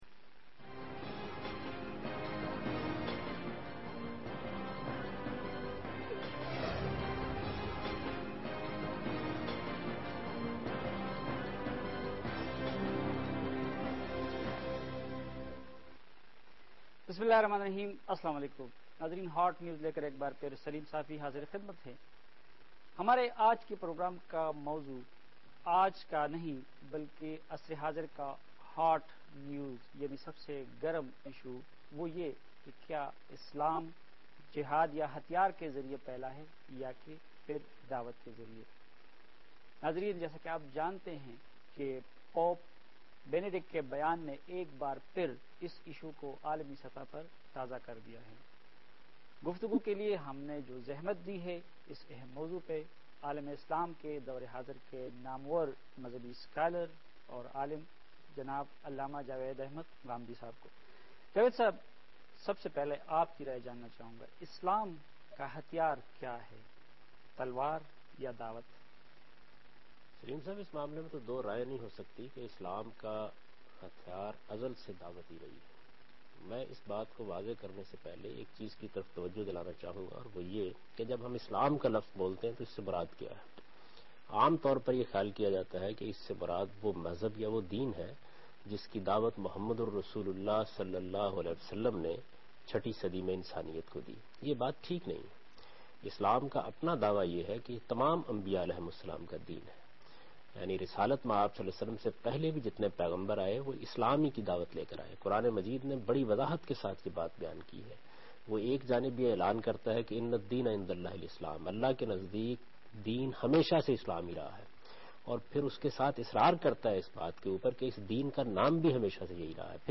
Questions and Answers-Ghamidi- Islam ka Hathyar ,Talwar ya Dawat? asked by todays youth and satisfying answers by Javed Ahmad Ghamidi.
Host: Saleem Safi)